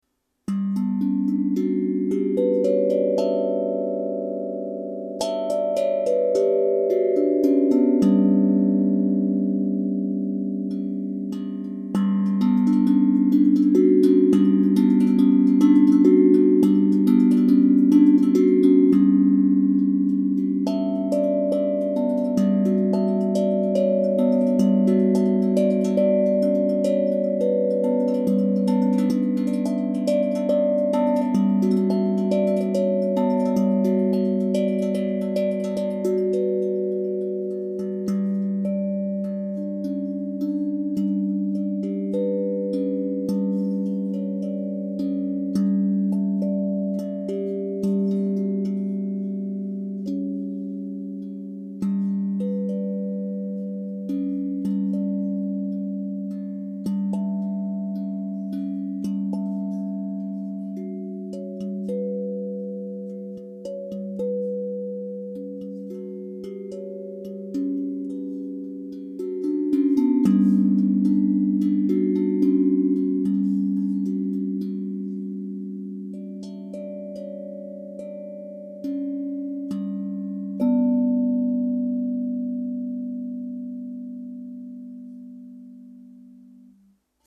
• Category: Glucophone